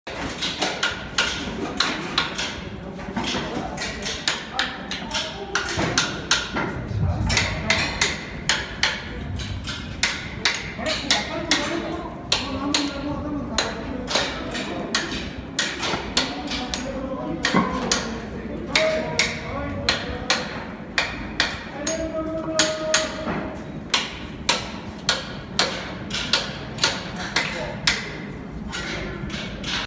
機械のモーター音やアナウンス、発車の合図......。地下鉄、都電荒川線、都営バスのそれぞれの場所でしか聞くことができない音を収録しました。
第1回地下鉄大江戸線「保線作業音」
第1回 地下鉄大江戸線「保線作業音」 レール交換をする保線作業時の音。大きな金槌のような棒を持ち、レールを叩いて音を聞き、問題がないか確認します。